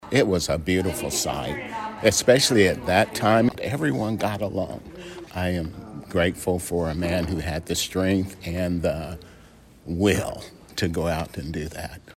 Special evening service honors the teachings, memory and legacy of Dr. Martin Luther King Jr Monday